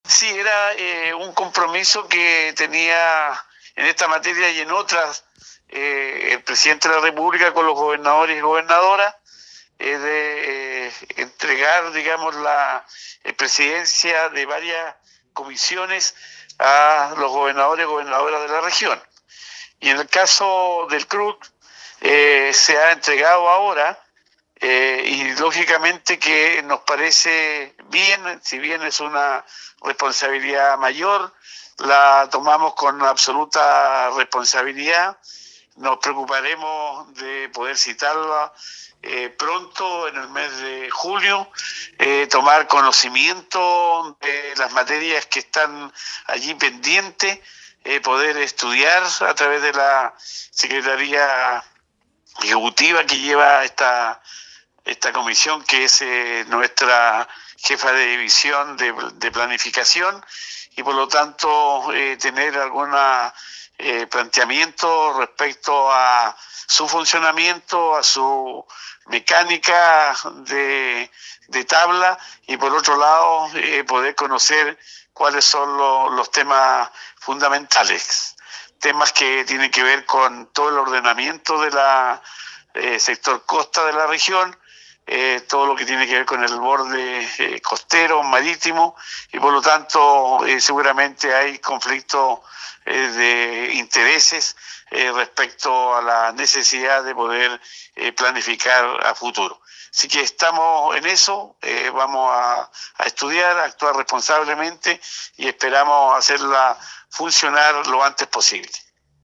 Cuña_Luis-Cuvertino_CRUBC.mp3